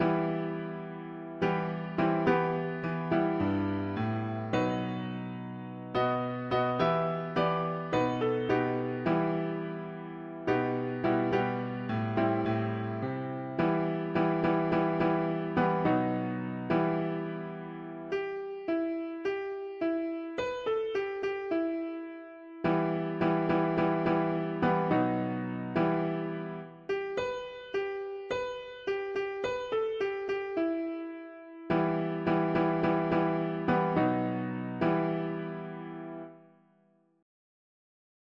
See that host all dressed in white, God’s gonna tro… english christian 4part chords
Key: E minor Meter: irregular with refrain